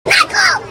explode1.ogg